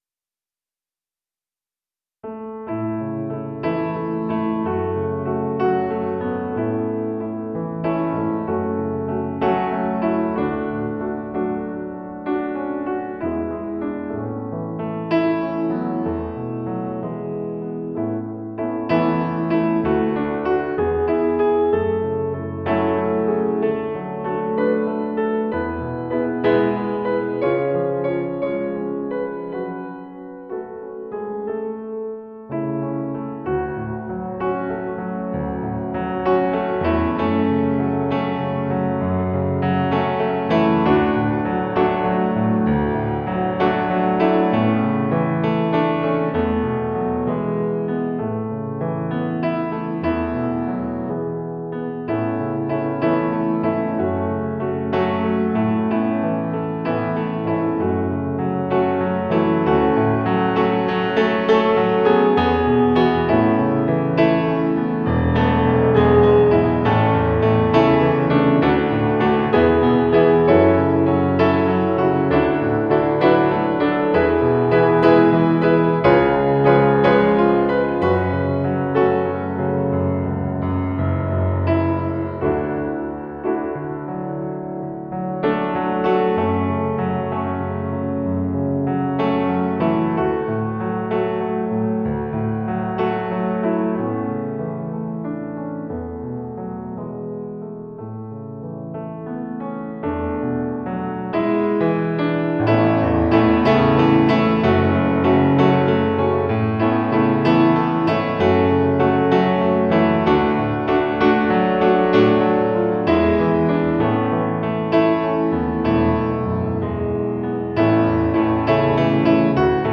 • Middagspianist
• Solomusiker